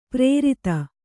♪ prērita